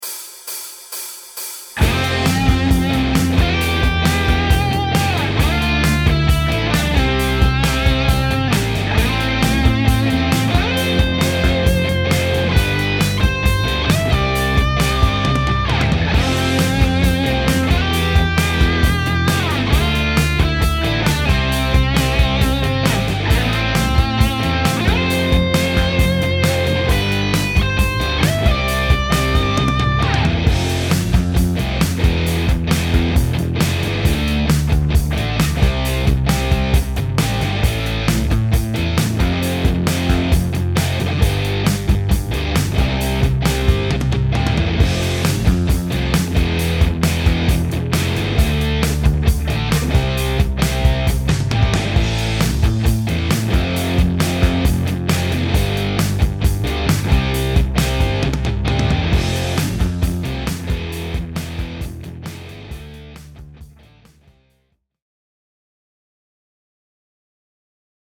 ・1小節目の1音目はグリッサンドで入り、2弦の6から8フレットへ素早いスライドフレーズ
・7小節目の2弦13、17、15フレットの移動はすべてスライドでつなぎます。